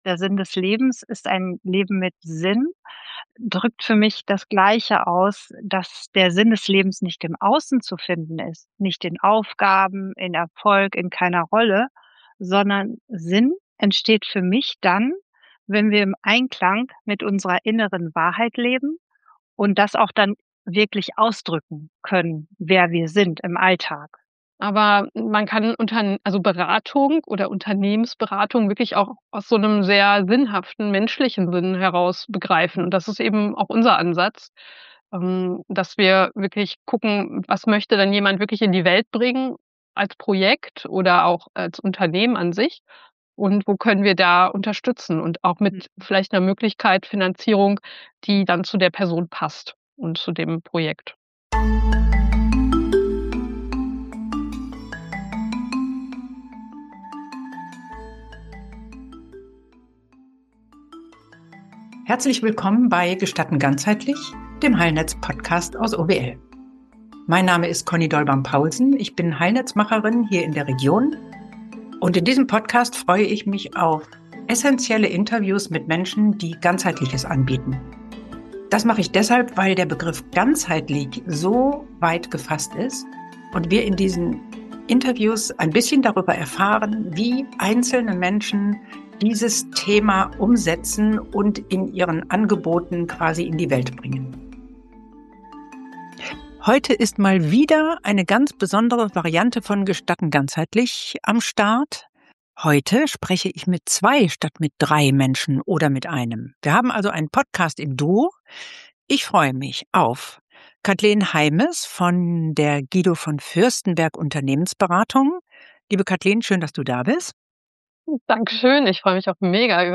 Hier hörst Du Interviews von Menschen, die im Heilnetz ganzheitliche Angebote machen und die im Podcast darüber sprechen, was ihnen dabei besonders wichtig...